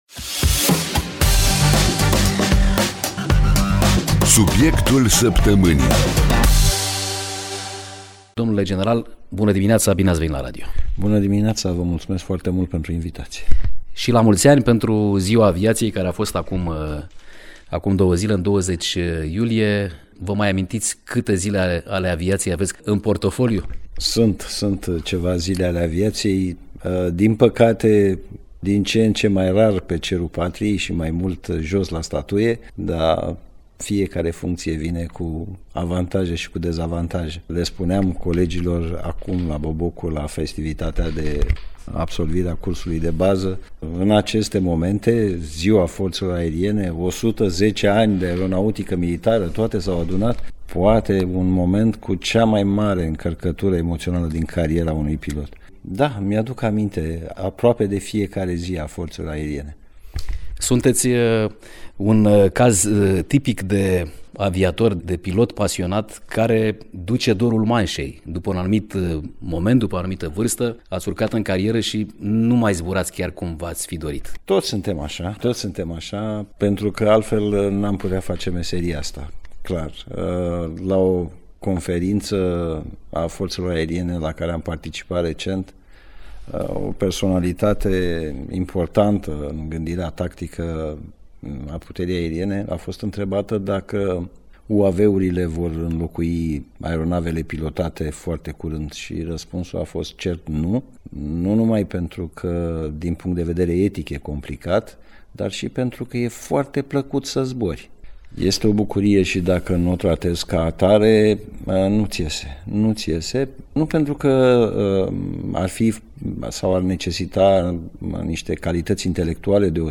De Ziua Forțelor Aeriene, de Ziua Aviației Române și a Forțelor Aeriene, Șeful Statului Major acestei categorii de forțe, generalul-locotenent Viorel Pană, a oferit un interviu la sediul Forțelor Aeriene, în care a vorbit despre misiunile piloților români alături de aliați și parteneri, dar și despre cât de pregătită este aviația militară din țara noastră să față provocărilor din mediu de securitate actual. 24 este sintagma domniei sale, 24 de ore din 24 de ore, 7 zile din 7.